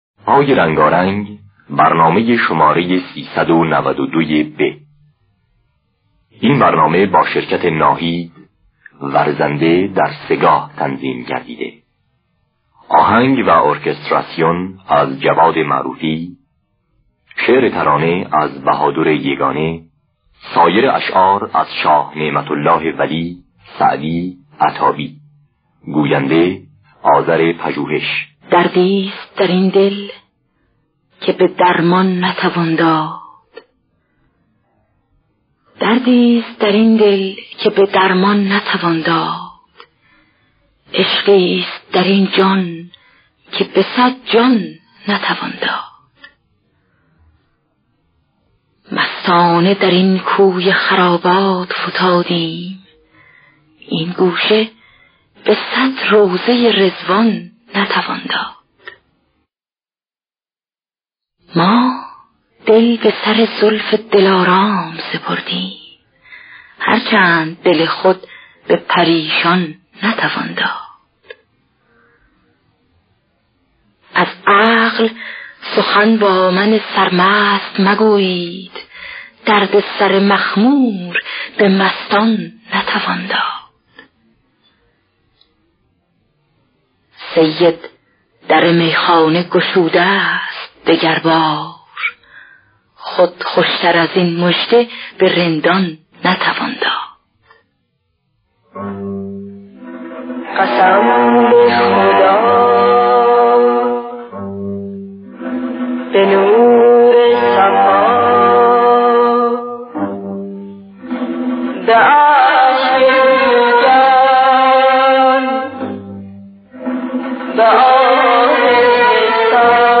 دانلود گلهای رنگارنگ ۳۹۲ب با صدای ناهید دایی‌جواد در دستگاه سه‌گاه.
دانلود گلهای رنگارنگ ۳۹۲ب - آرشیو کامل برنامه‌های رادیو ایران